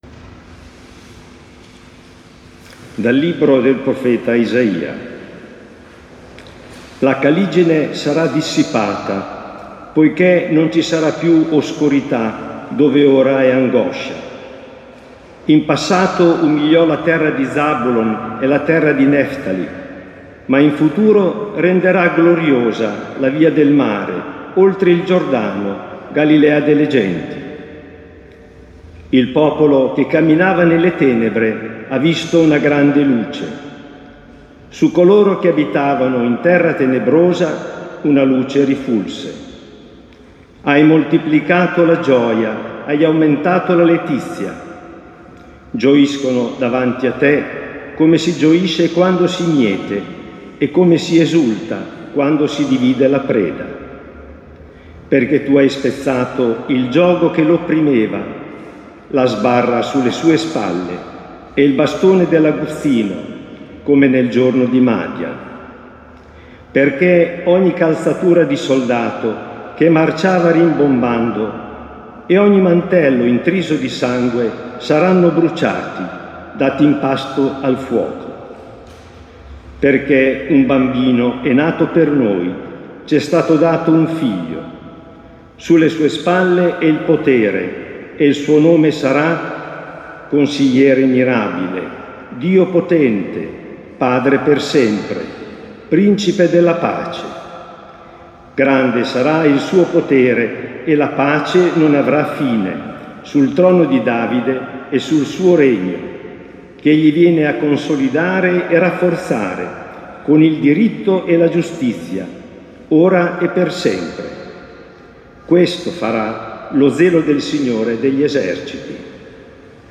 Avvento 2025